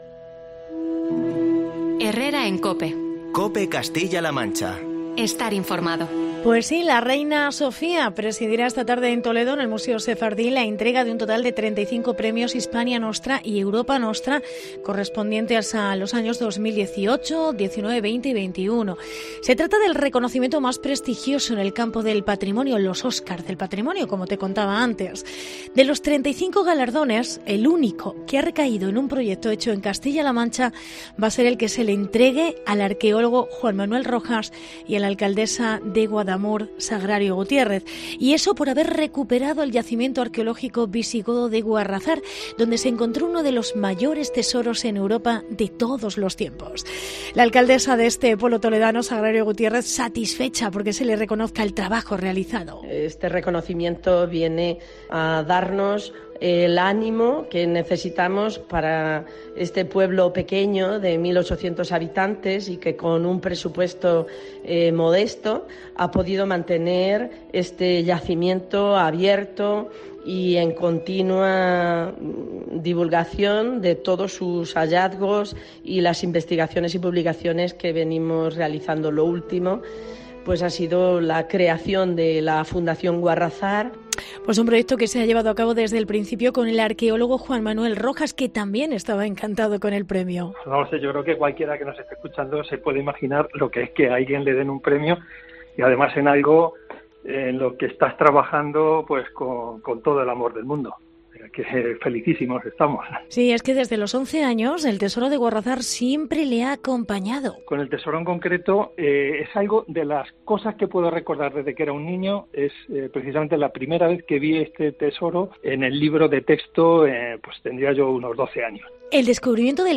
Reportaje yacimiento Guarrazar en Guadamur